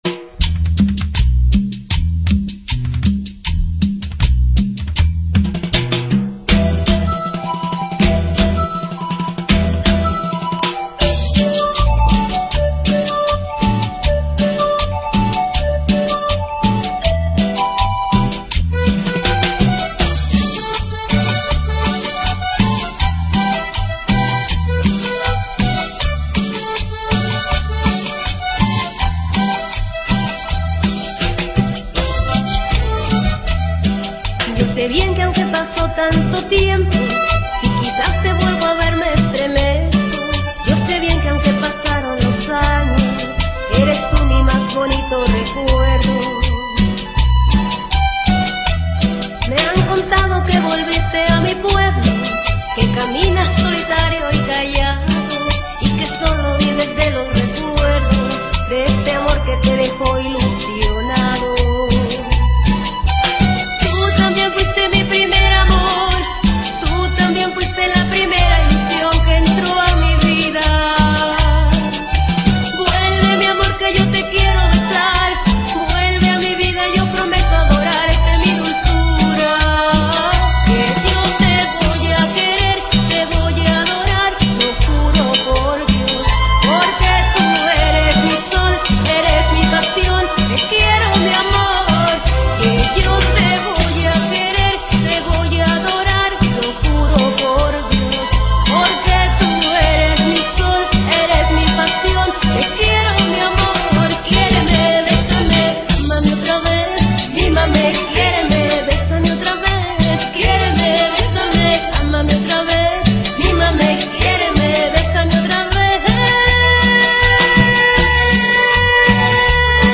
sintetizadores, guitarra electrica y bajo